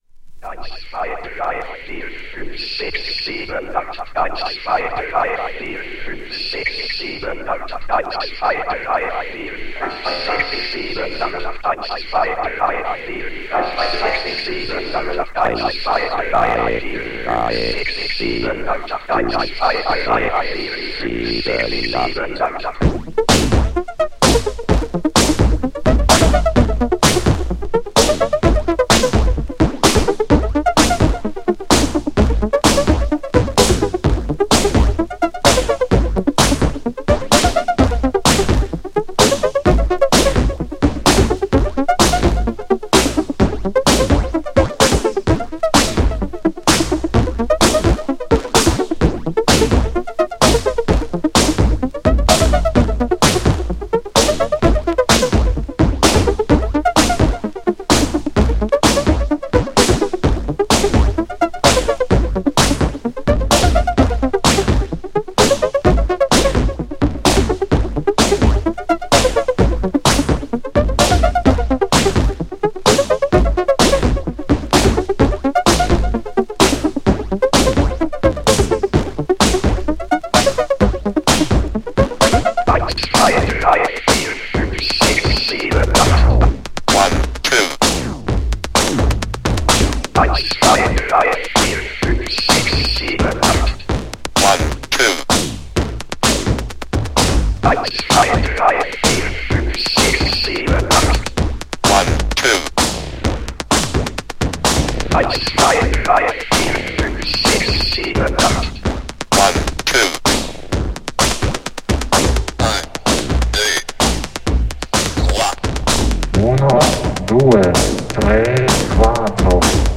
GENRE Dance Classic
BPM 121〜125BPM
エレクトロ
テクノ
空間的